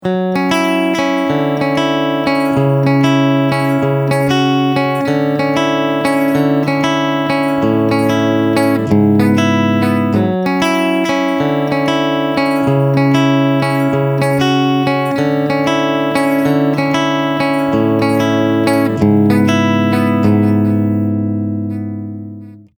Here we have a short recording of a clean guitar:
It sounds nice already, and we feel that double-tracking would literally be ‘too much.’
ThreeD-Natural-Stereo-Guitar-DRY.mp3